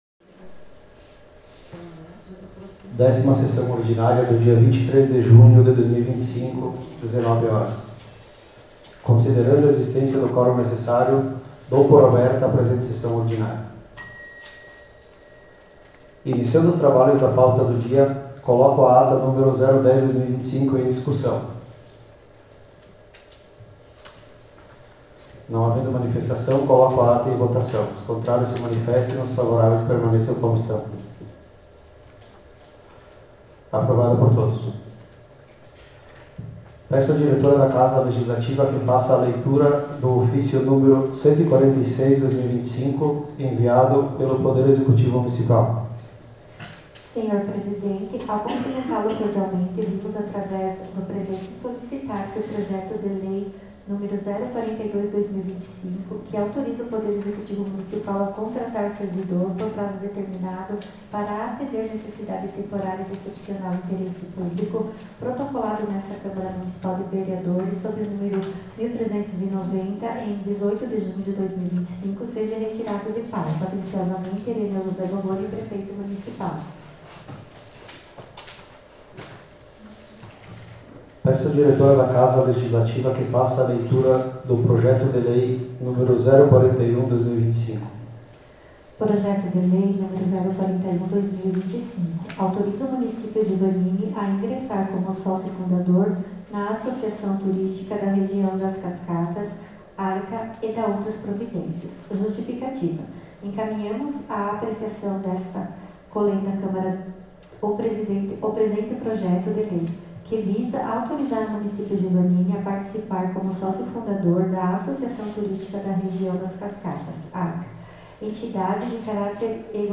Em anexo arquivo de gravação em áudio da Sessão Ordinária realizada na Câmara de Vereadores de Vanini na data de 23/06/2025.
Gravação em áudio da Sessão Ordinária do dia 23 de junho de 2025